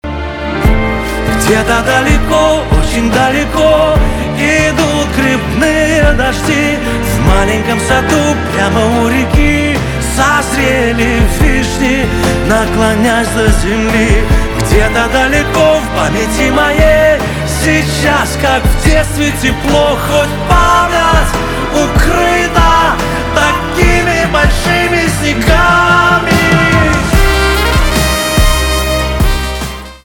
эстрада
грустные , чувственные
гитара , барабаны , труба
поп